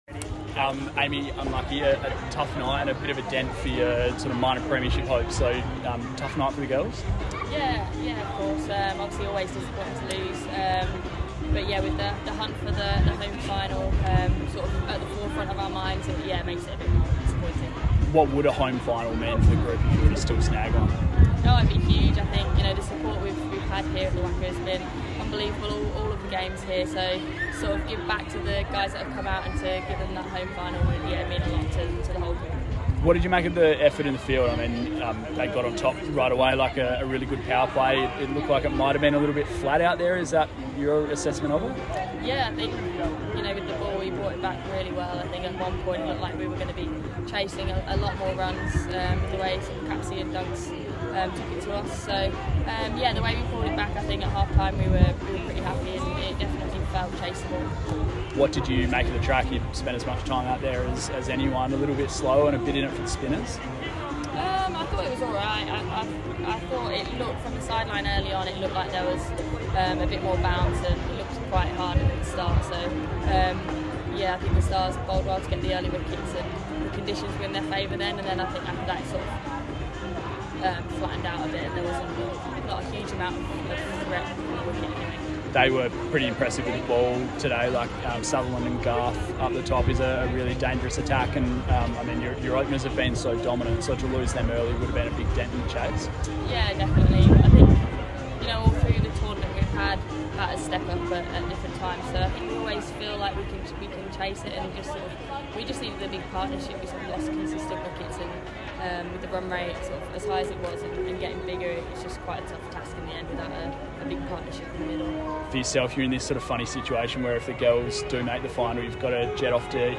Scorchers batter Amy Jones (42 off 30) spoke to media following the Scorchers 33-run defeat to Melbourne Stars at the WACA Ground tonight.